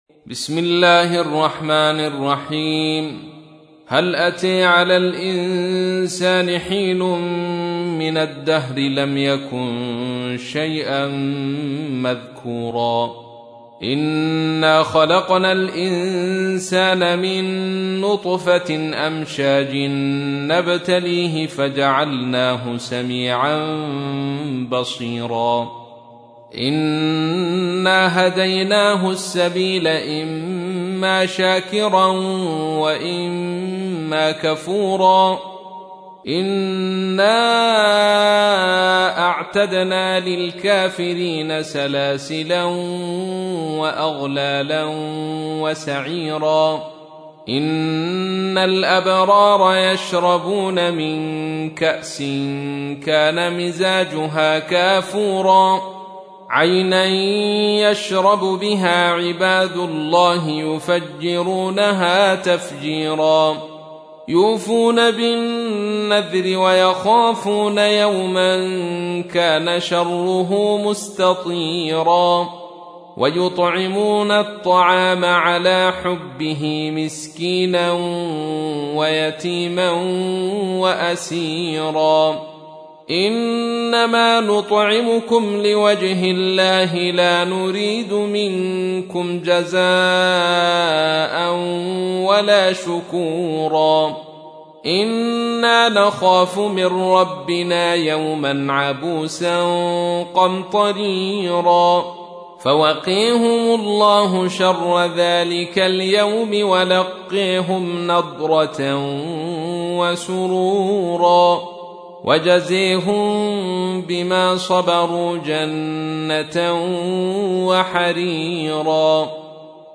تحميل : 76. سورة الإنسان / القارئ عبد الرشيد صوفي / القرآن الكريم / موقع يا حسين